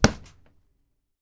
Claps
SLAP C    -S.WAV